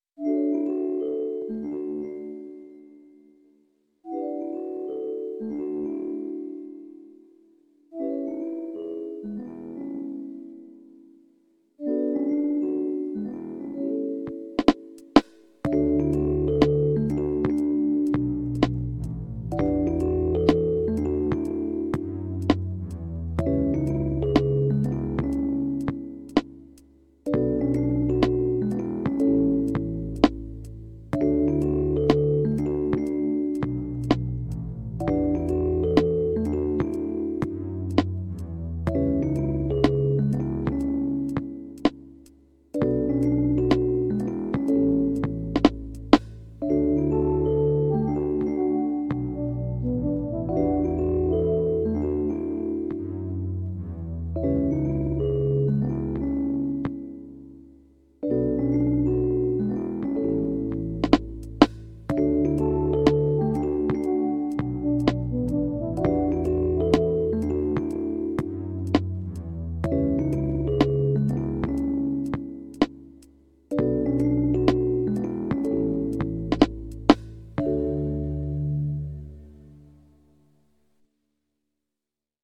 自作曲：夜明け(B,BPM62.0)